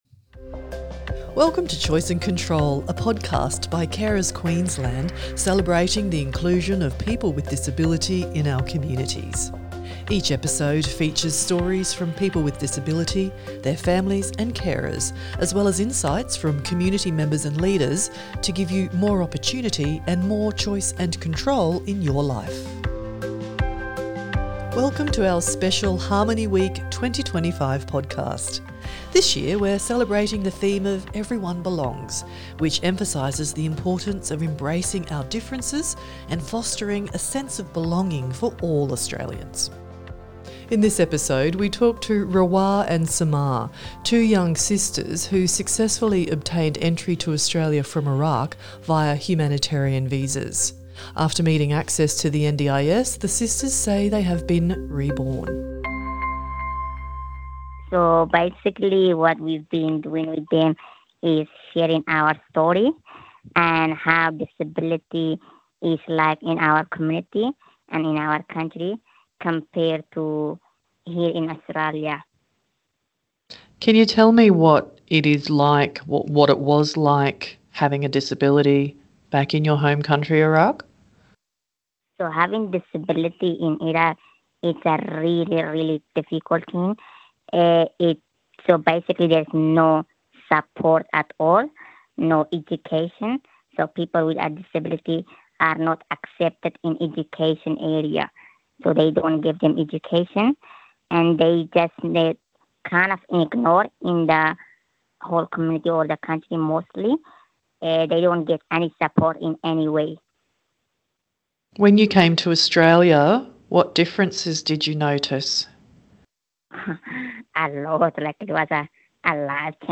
Welcome to our special Harmony Week 2025 podcast.